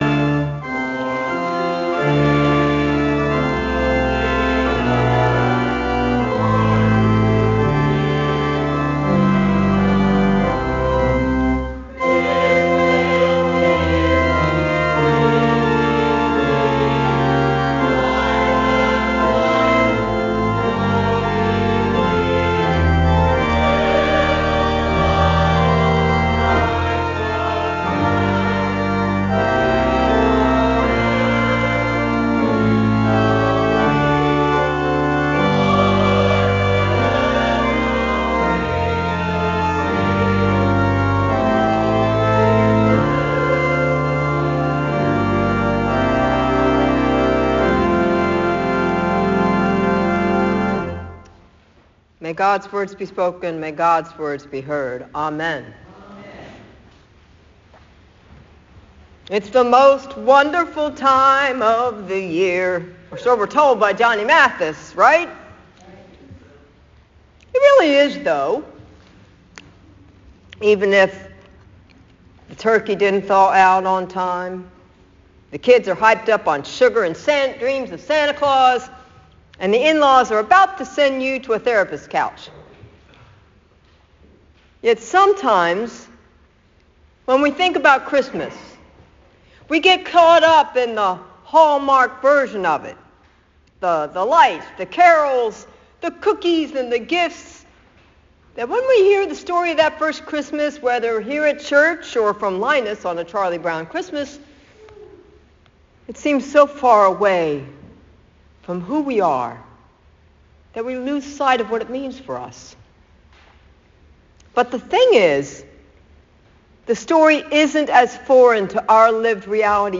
Rec-001-Sermon-Christmas_Eve-11pm.m4a